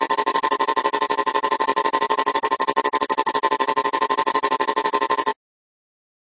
描述：这些是175 bpm的合成层背景音乐可以在你的混音中提出并用作合成器导致可以与鼓和贝司一起使用。
标签： 循环 房子 电子 狂野 合成器 音乐 电子乐 节拍 效果 俱乐部 声音 恍惚间 舞蹈 贝司 大气 FX
声道立体声